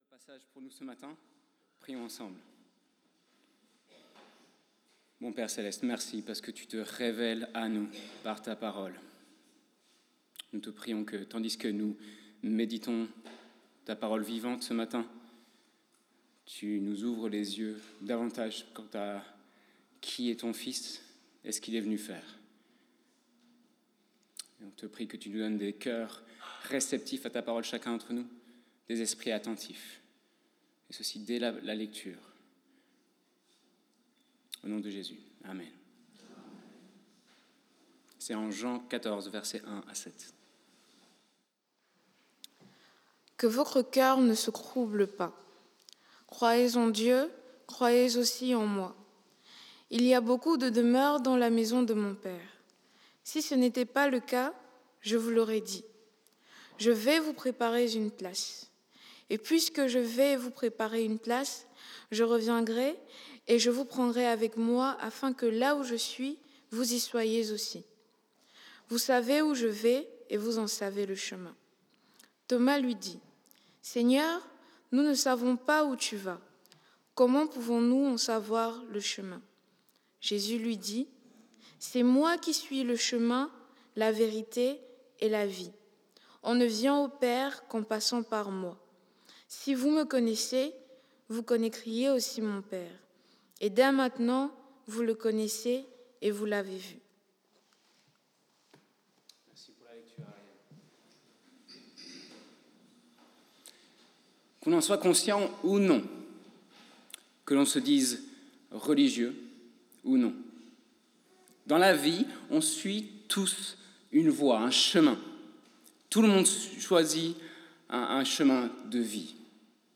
Ecouter le message